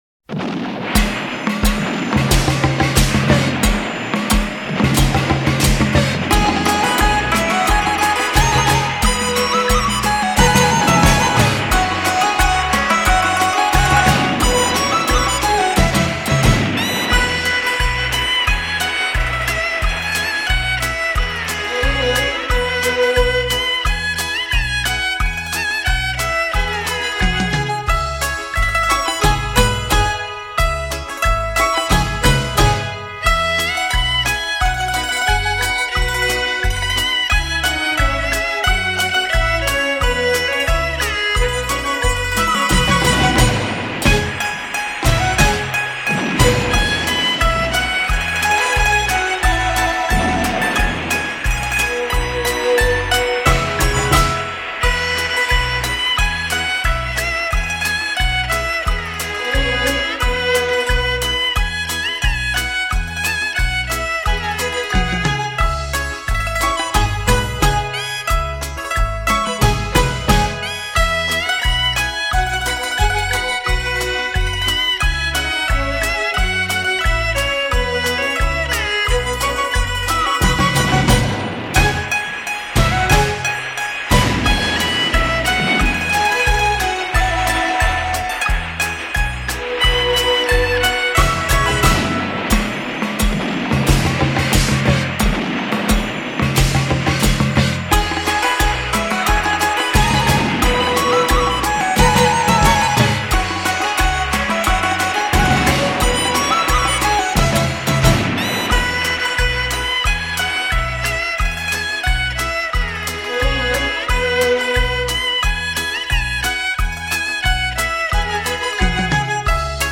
华乐精彩争鸣 欢乐洒遍人间
超高频率 节庆极品
鼓声喧闹绕场欢庆 百乐争鸣吉祥如意